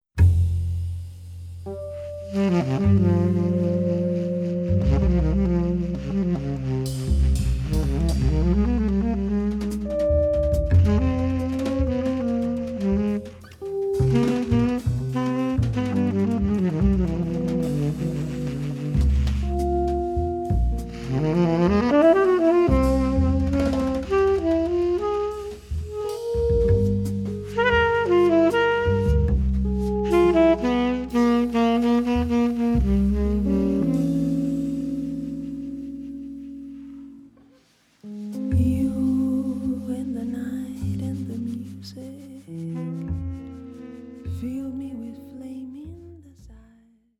vocals
tenor sax
guitar
double bass
Drums